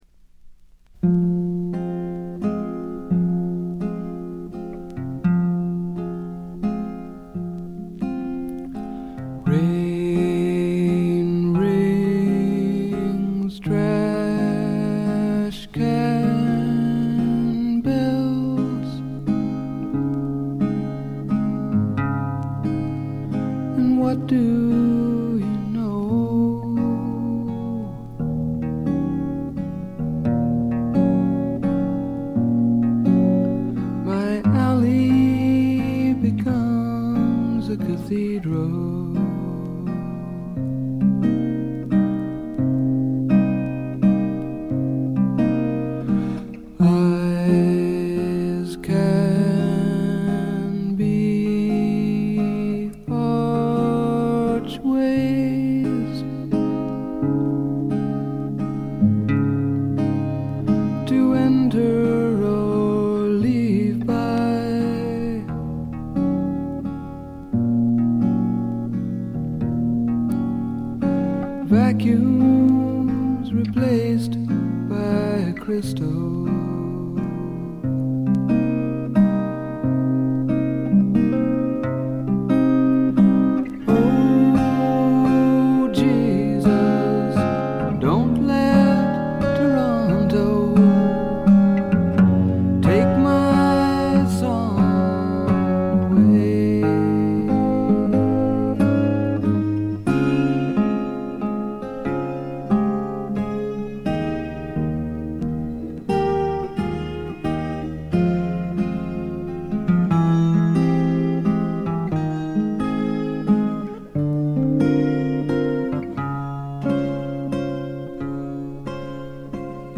部分試聴ですが、微細なチリプチごくわずか。
試聴曲は現品からの取り込み音源です。